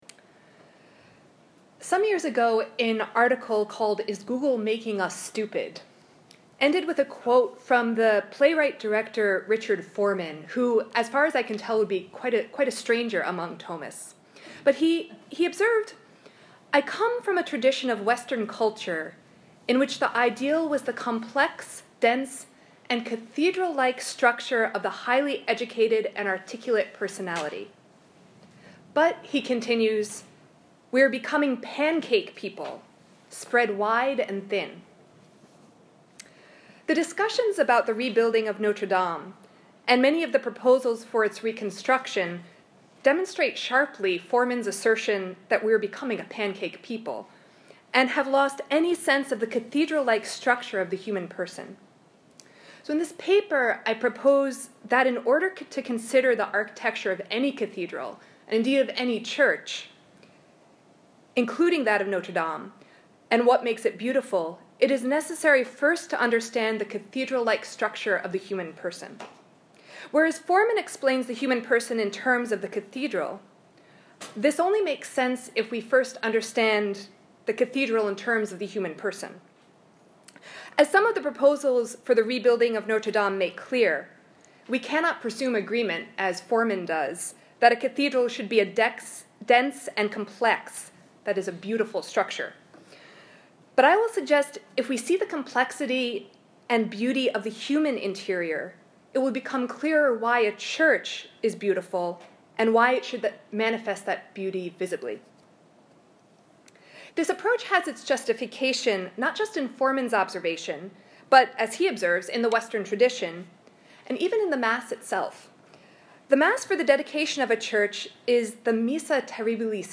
This lecture was given at New York University on 16 November 2019 at a symposium gathering scholars and architects in light of the recent catastrophe at Notre Dame Cathedral in Paris to consider the purpose of sacred architecture, the nature of beauty and the issues with early proposals for rebuilding Notre Dame that are rooted in post-modern ideas about art and architecture.